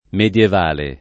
vai all'elenco alfabetico delle voci ingrandisci il carattere 100% rimpicciolisci il carattere stampa invia tramite posta elettronica codividi su Facebook medievale [ med L ev # le ] o medioevale [ m H d L oev # le ] agg. — non mediovale